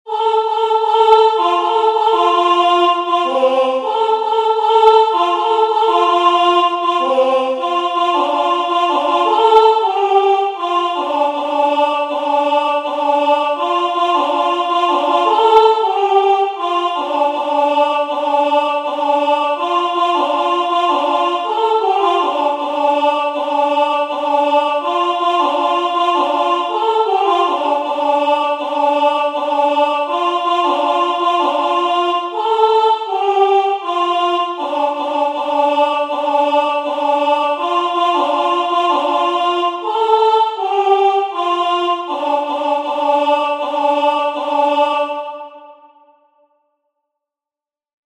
The tune is particularly soft and pleasing, and the song remains a standard favorite.
song-arapaho01.mp3